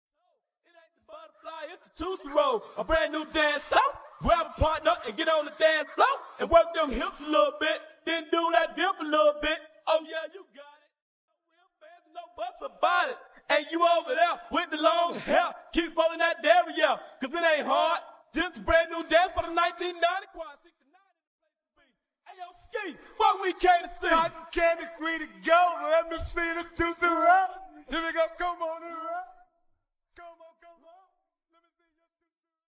Backing & Leading Vocals at REQUEST!